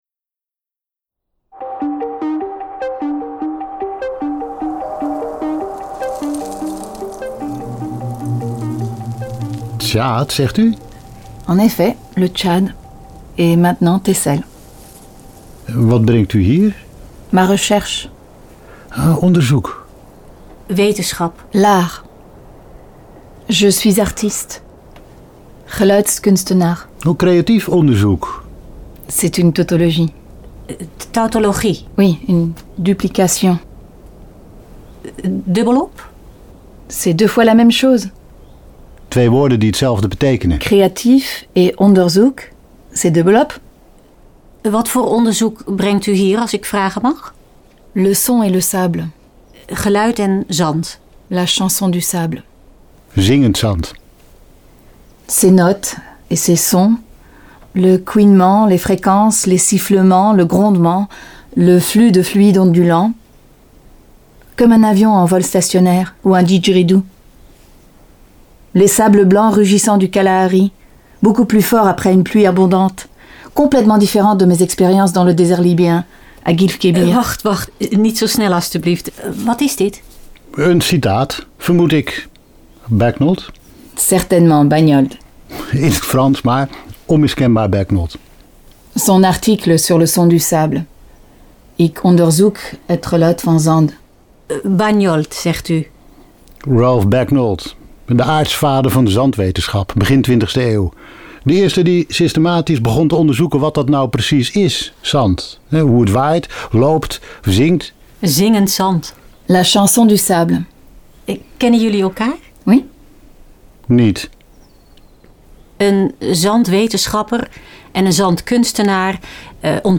Zandspel, een wandel-podcast in zes episoden
Stemmen